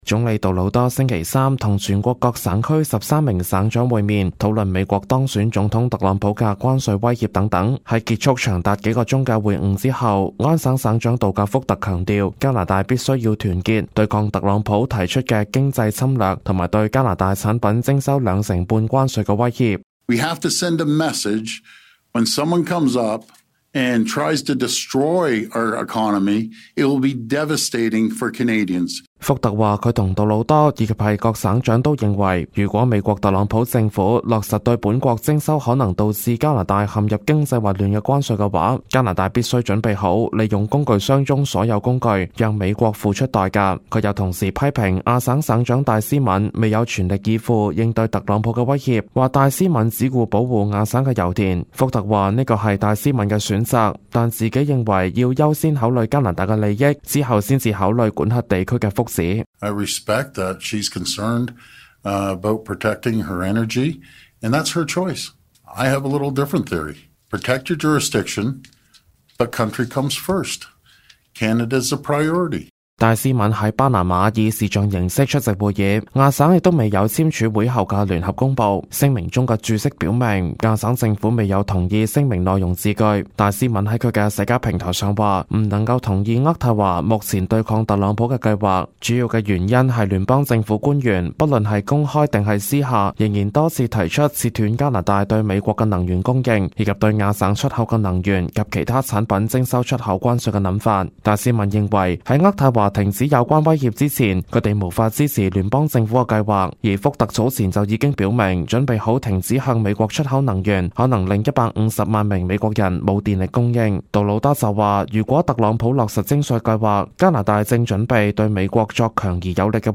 news_clip_22168.mp3